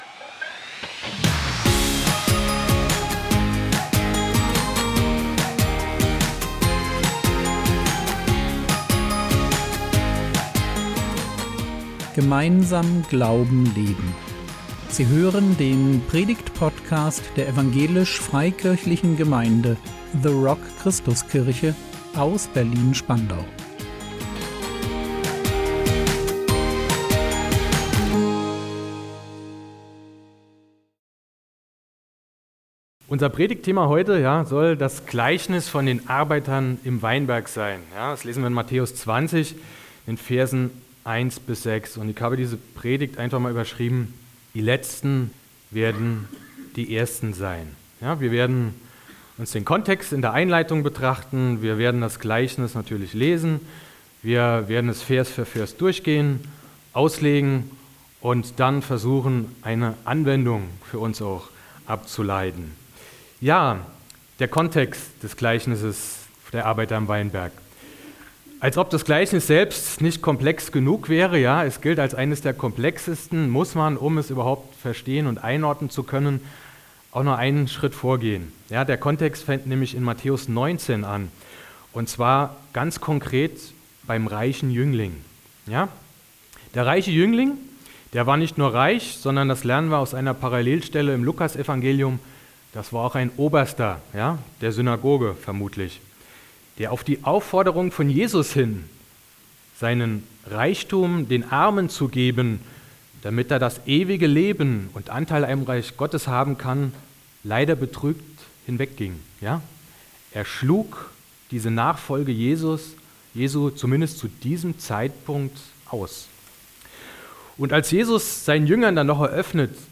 Die Letzten werden die Ersten sein | 01.12.2024 ~ Predigt Podcast der EFG The Rock Christuskirche Berlin Podcast